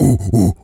pgs/Assets/Audio/Animal_Impersonations/gorilla_chatter_06.wav at master
gorilla_chatter_06.wav